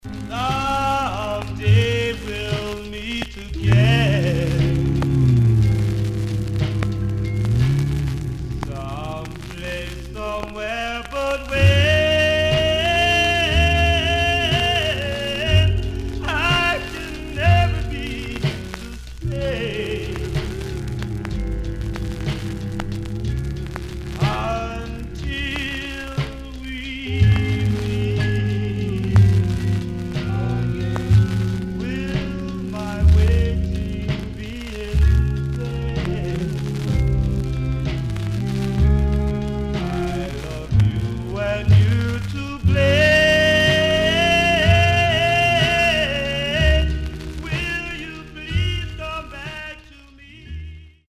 Condition Surface noise/wear Stereo/mono Mono
Male Black Groups